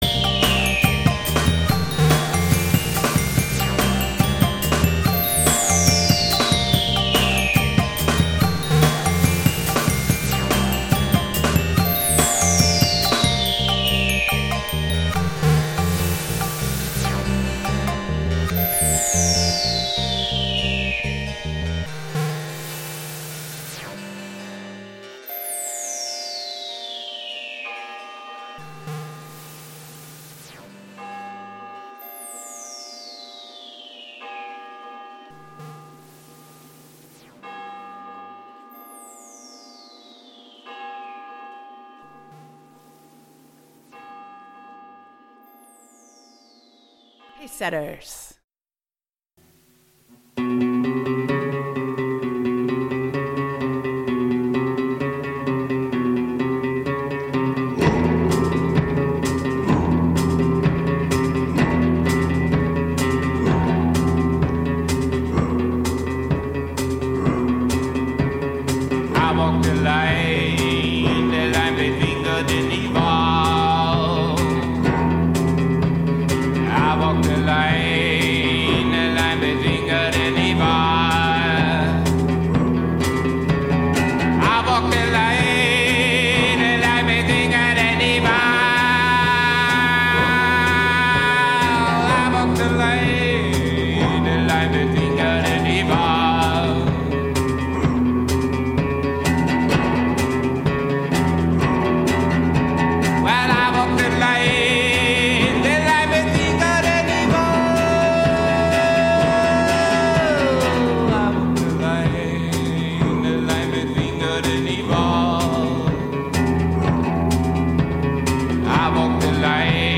Live from Pacesetters Kitchen Radio (Malaga, Spain)
Live from Pacesetters Kitchen Radio (Malaga, Spain): Basspistol Radio (Audio) Nov 28, 2025 shows Live from Pacesetters Kitchen Radio (Malaga, Spain) Basspistol Radio Station! 777% without commercials! RobotDJ-sets and live interventions!